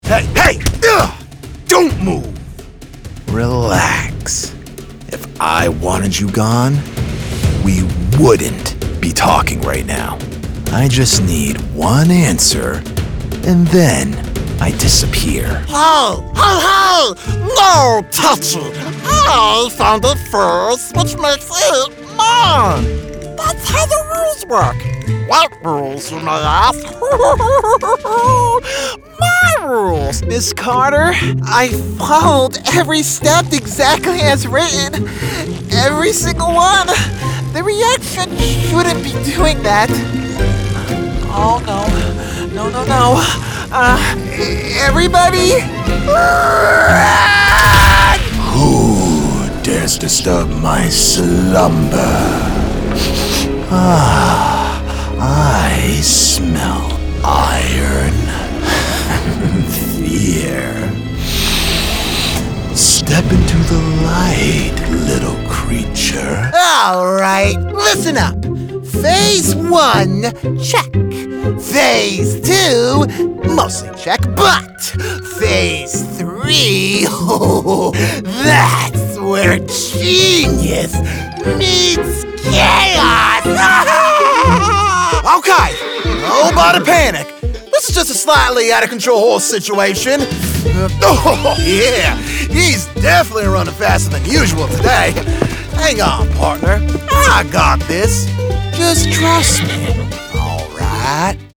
Professional Male Voice Talent
Animation-Demo.mp3